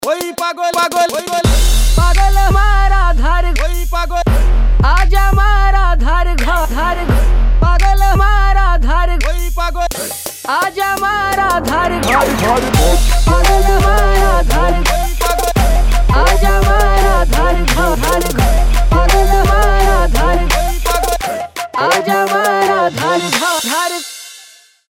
• Качество: 320, Stereo
Trap
Arabian
Трап музыка с Арабским диалектом